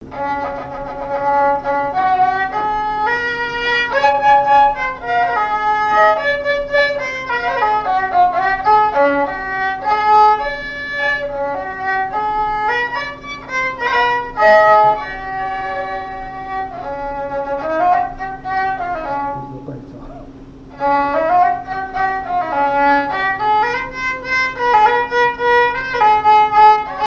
Mémoires et Patrimoines vivants - RaddO est une base de données d'archives iconographiques et sonores.
danse : polka
Répertoire de marches de noce et de danse
Pièce musicale inédite